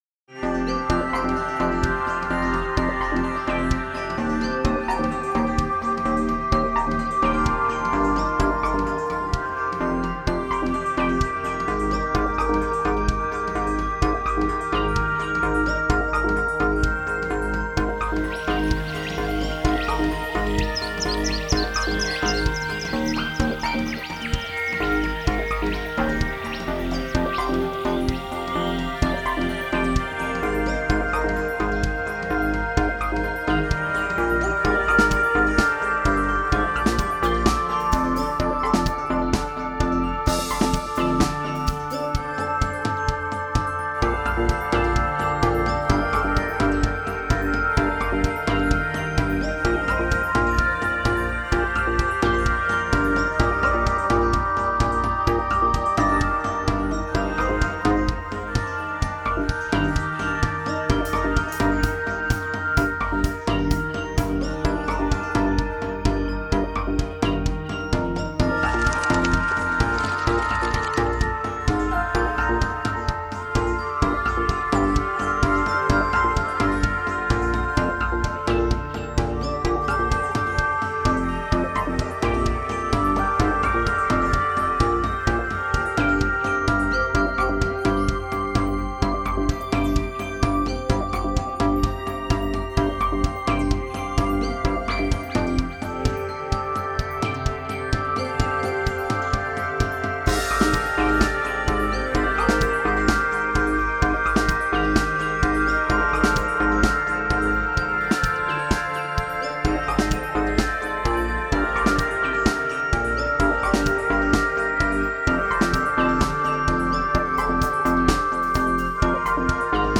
BGM
アップテンポインストゥルメンタルロング